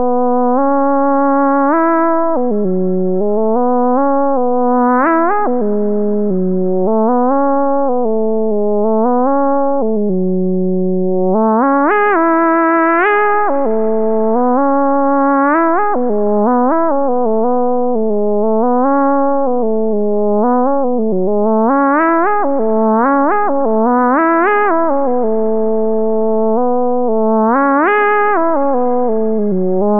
A randomly generated line is used to produce a quantized melody via qgliss. this line also maps to other parameters in the patch as an expression curve.